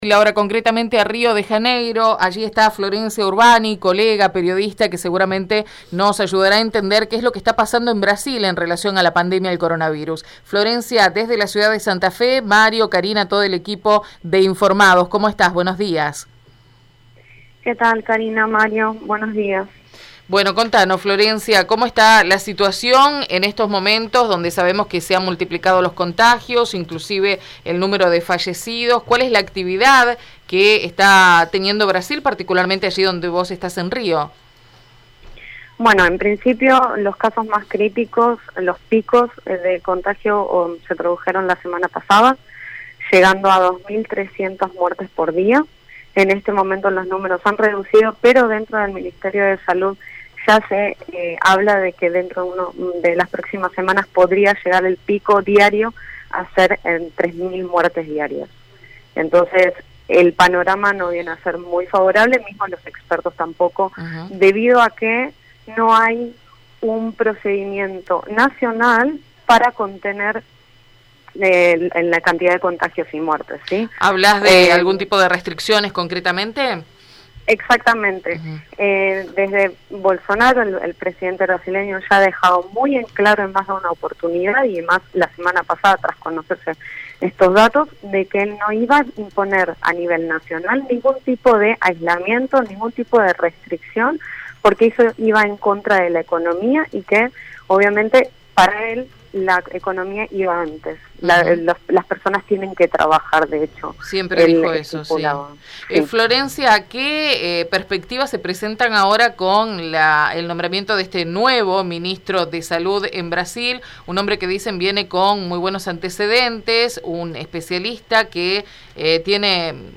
En diálogo con Radio EME, la periodista argentina en Brasil